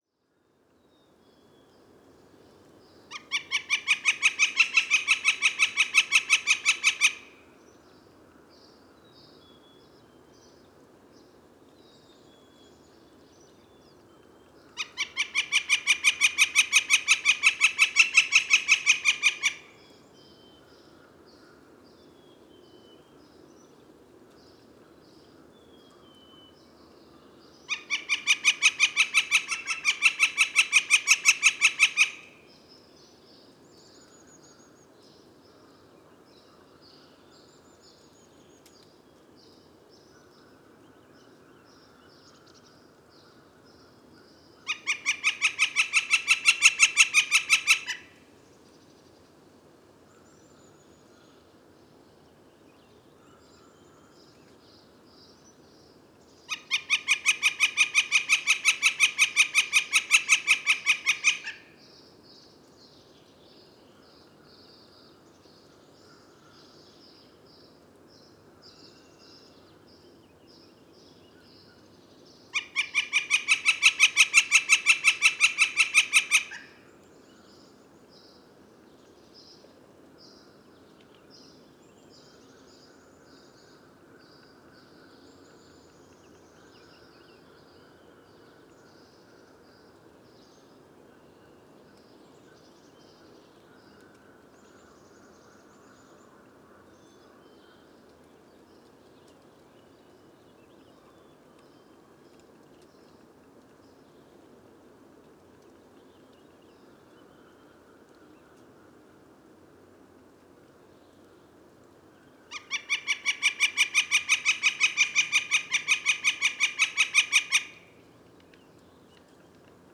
Pic flamboyant – Colaptes auratus
Chant – ‘Keek’ series Rocher Blanc, Rimouski, QC, 48°26’26.6″N 68°34’46.6″W. 5 mai 2019. 9h00.